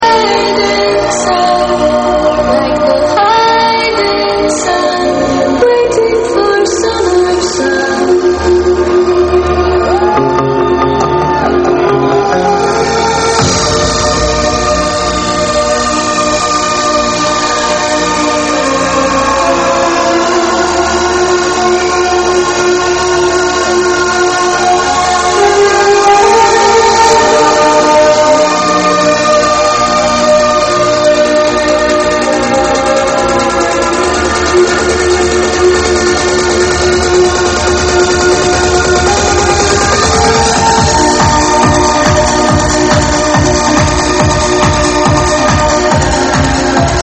I'm searching for the first song, the melodic one.